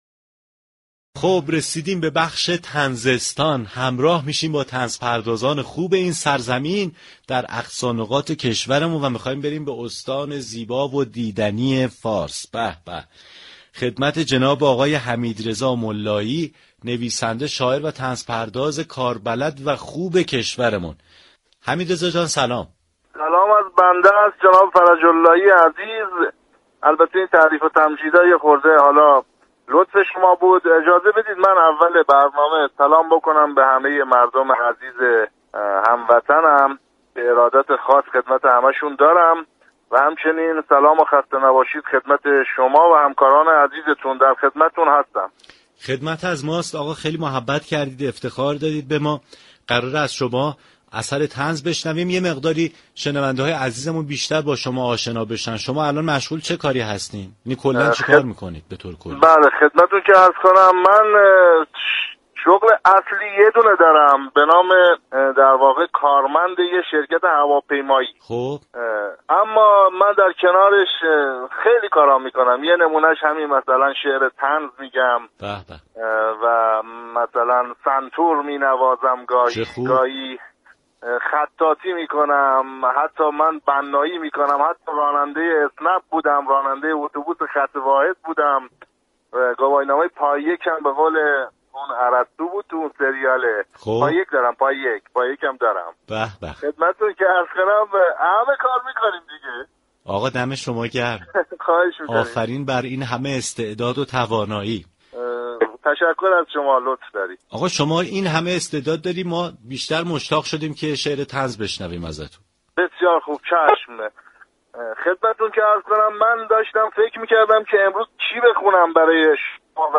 شنونده گفتگوی رادیو صبا